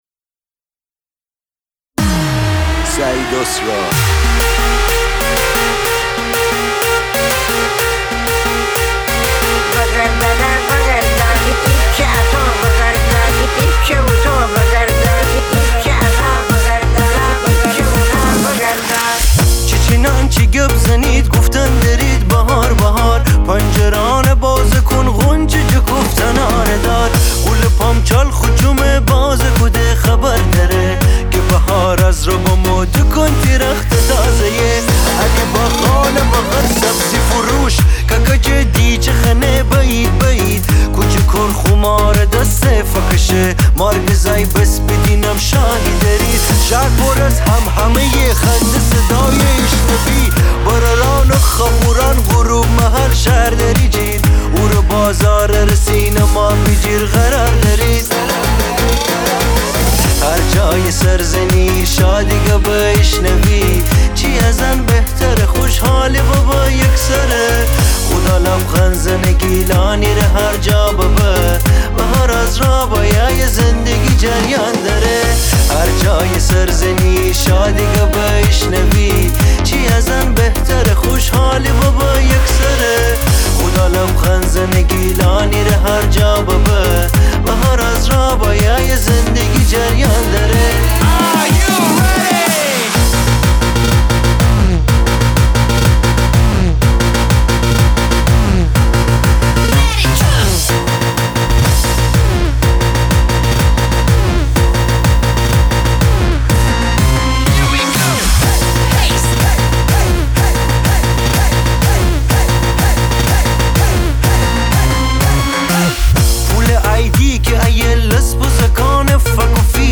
آهنگ شاد گیلانی